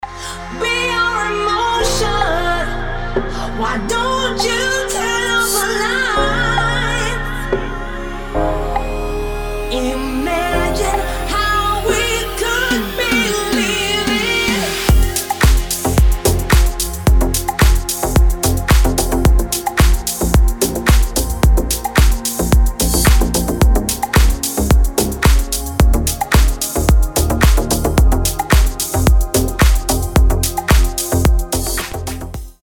• Качество: 320, Stereo
красивые
женский вокал
Стиль: deep house, nu disco